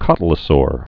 (kŏtl-ə-sôr)